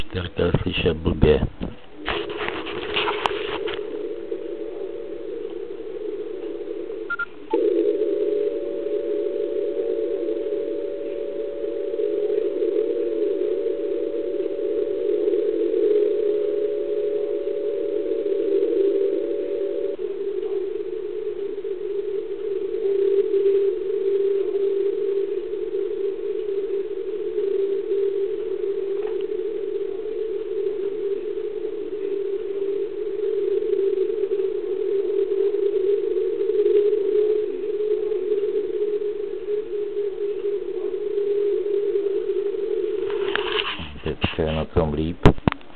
Podmínky dobré, ale slyšitelnost lišek špatná.